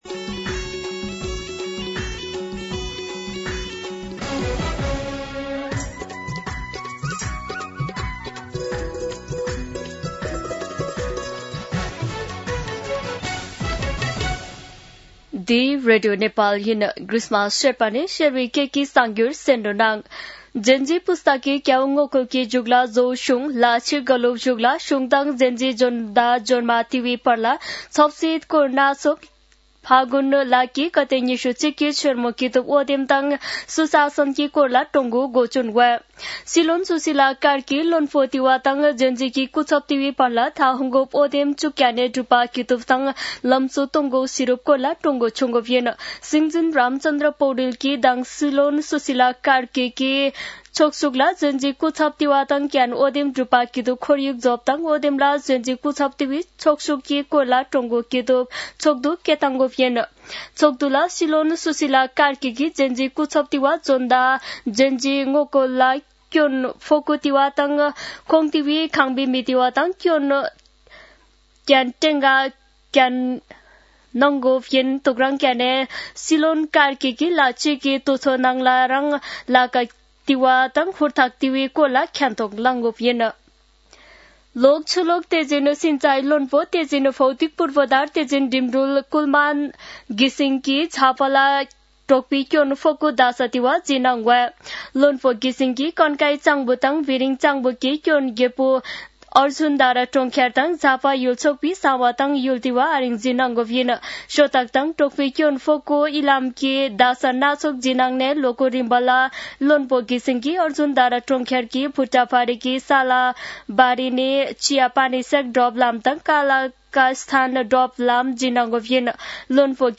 शेर्पा भाषाको समाचार : २६ असोज , २०८२